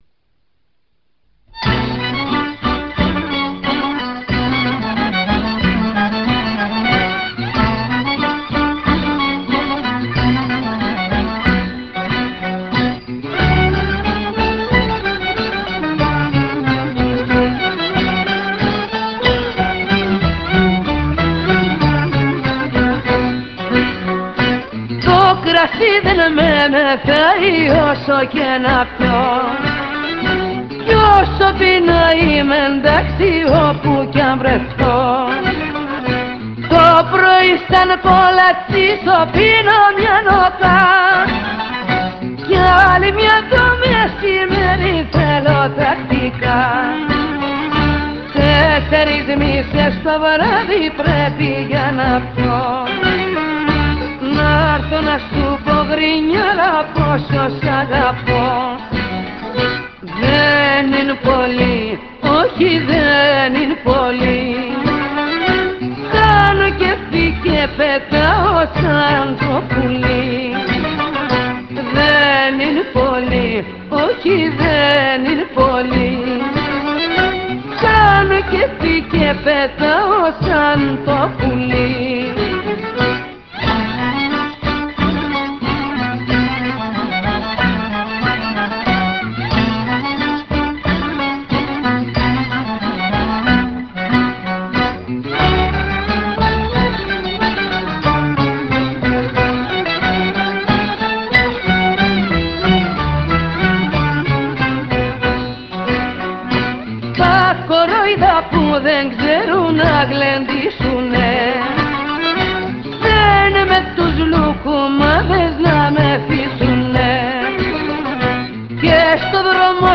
Aptaliko